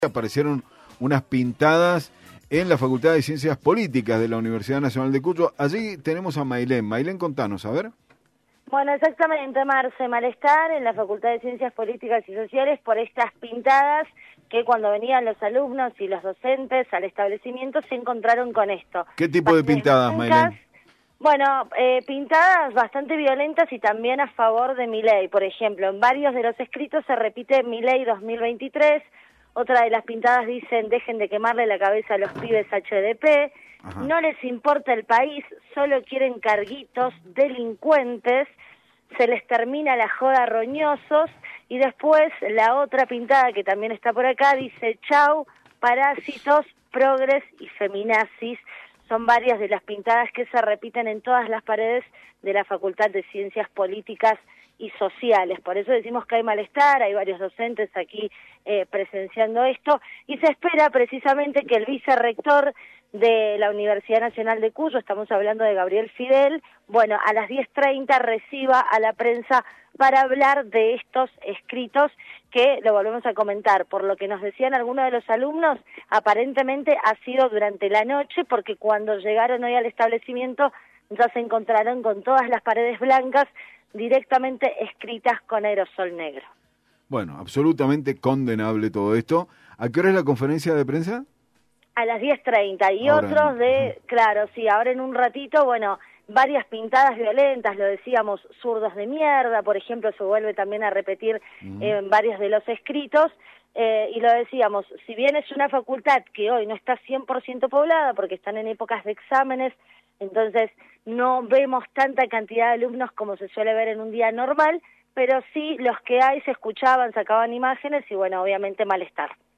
Móvil de LVdiez desde Facultad de Ciencias Politicas de la UNCuyo